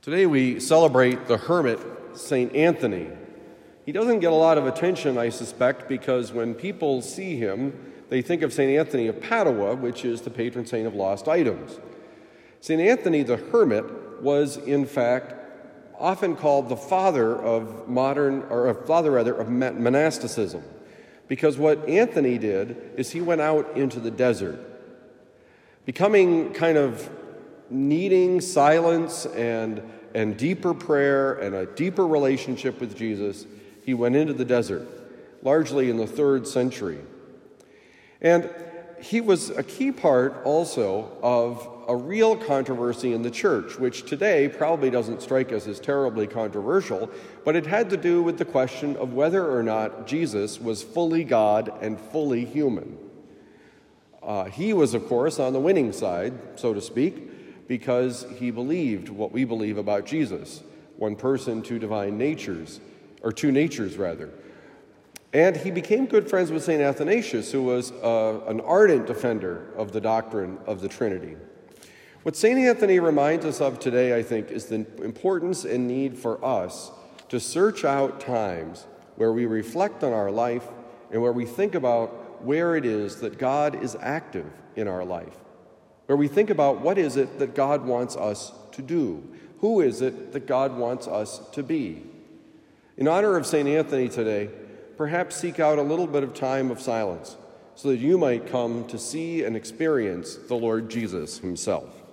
Saint Anthony: But not the one you might be thinking about: Homily for Tuesday, January 17, 2023
Given at Christian Brothers College High School, Town and Country, Missouri.